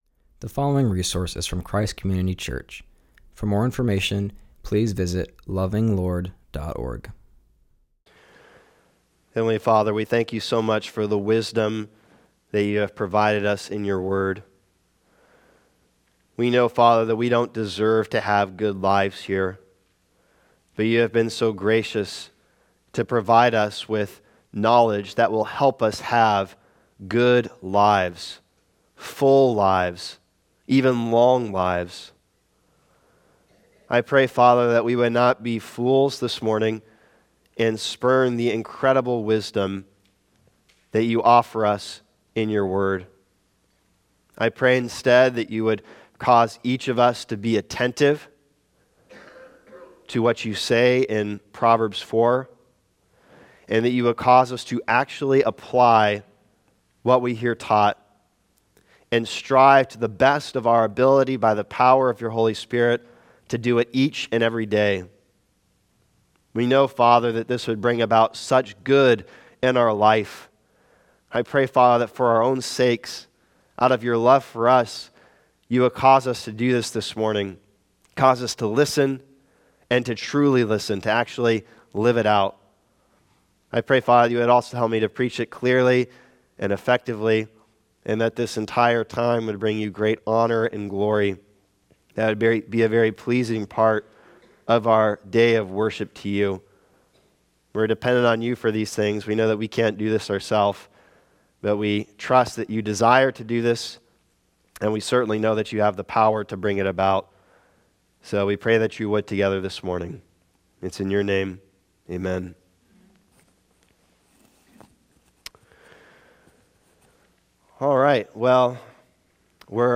preaches from Proverbs 4:20-23.